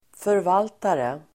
Uttal: [förv'al:tare]